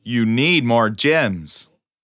8 bits Elements
Voices Expressions Demo
YouNeedMoreGems_1.wav